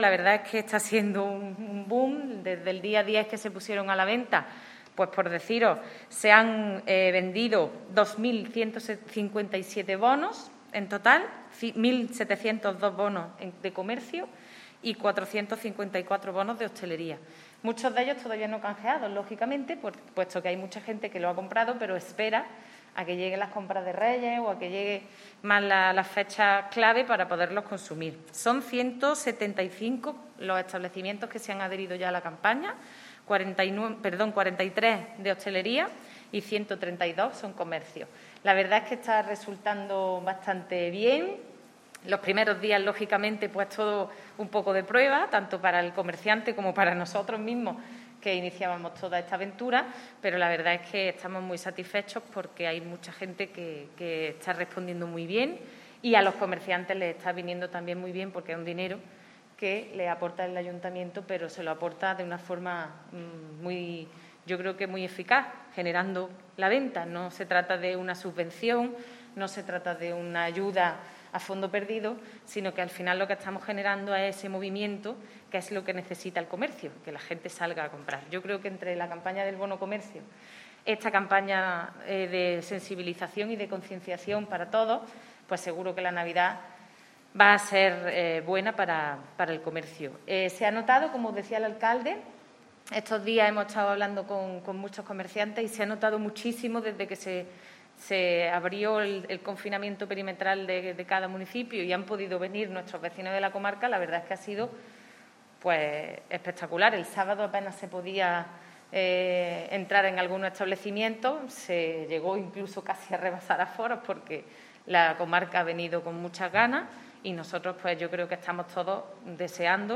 La teniente de alcalde Ana Cebrián explicaba durante su intervención en la rueda de prensa que este nuevo spot promocional para concienciar sobre la necesidad de realizar nuestras compras en el comercio local forma parte de la campaña "Antequera, tus compras siempre aquí" iniciada en el mes de mayo como medida de apoyo al sector del comercio y la hostelería en plena crisis del coronavirus.
Cortes de voz